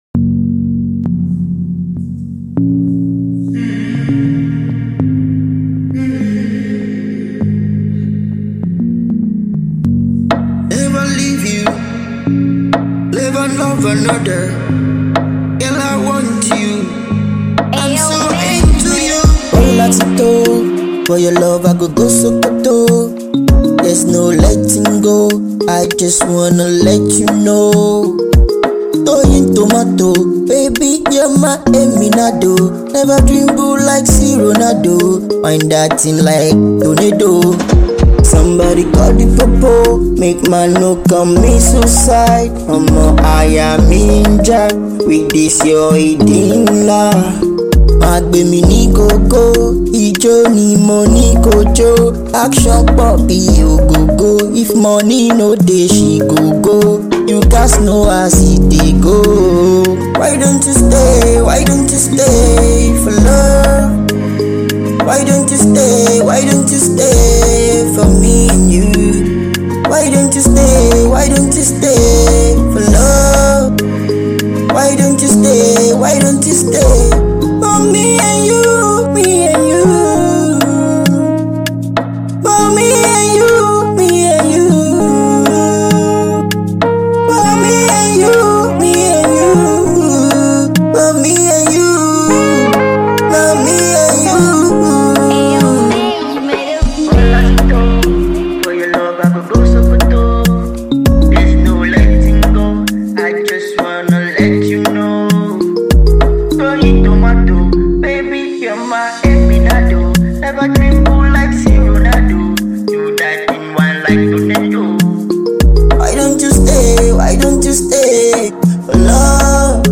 Afrobeat and contemporary music
infectious beats